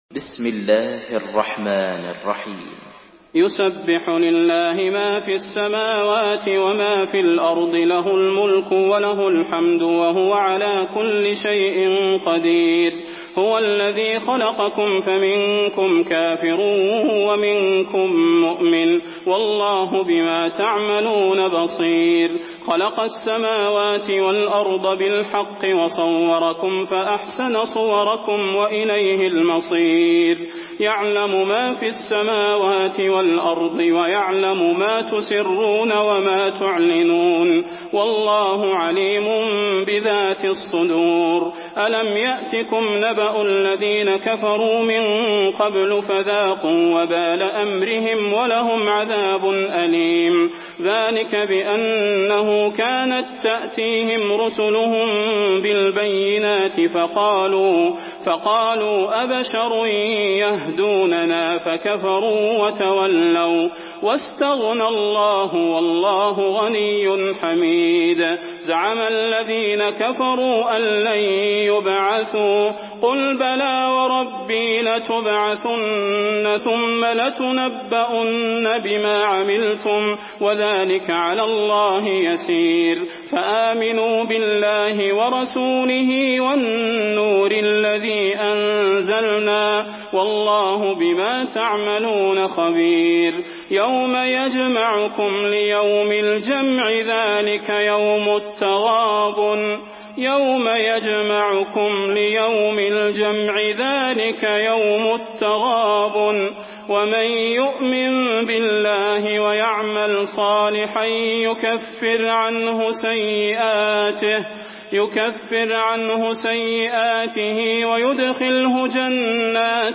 Surah At-Taghabun MP3 in the Voice of Salah Al Budair in Hafs Narration
Murattal